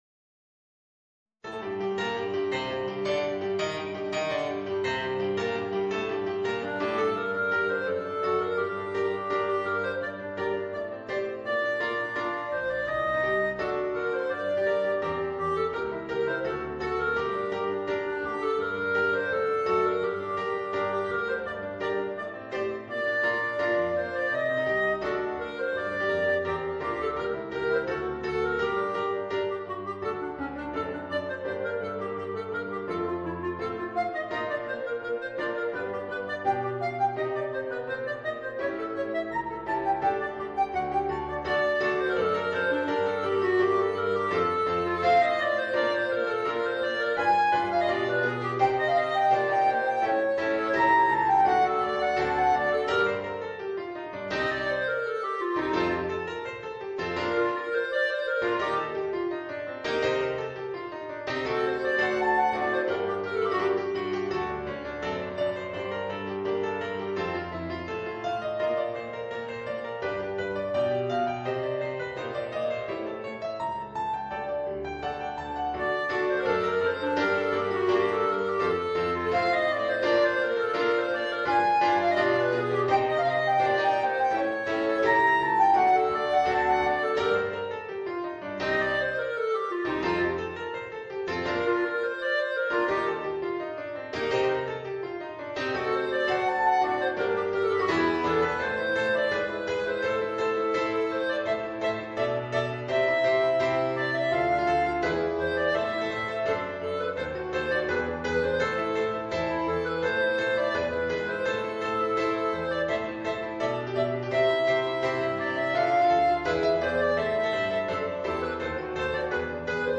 Voicing: Clarinet and Piano